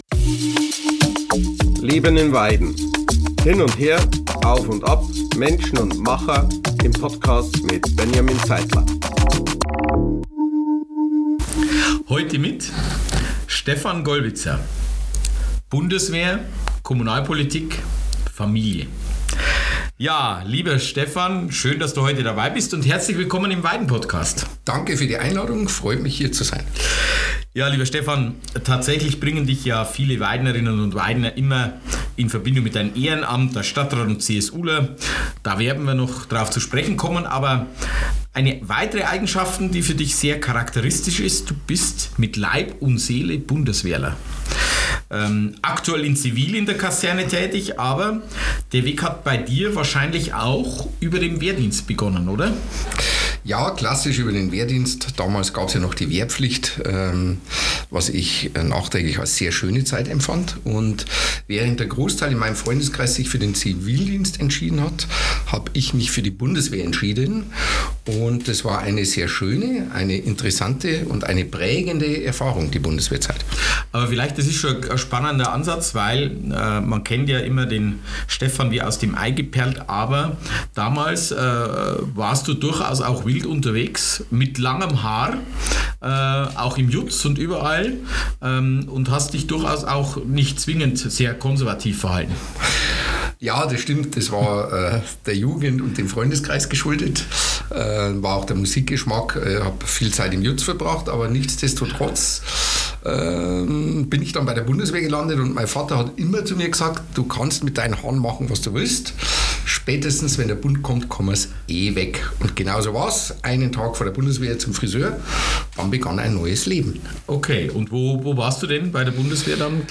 🎙 Folge #49 – Dienst, Dialog und Durchhaltevermögen: Stephan Gollwitzer über Bundeswehr, Ehrenamt und Familienleben In dieser Folge ist Stephan Gollwitzer zu Gast – Bundeswehrbeamter, CSU-Stadtrat, VfB-Vorstand und Familienmensch. Ein Gespräch mit einem, der Weiden nicht nur kennt, sondern seit Jahrzehnten mitgestaltet. Wir sprechen über seinen Weg von den Auslandseinsätzen im Kosovo und Afghanistan bis hin zur heutigen Tätigkeit in der Kaserne in ziviler Verantwortung.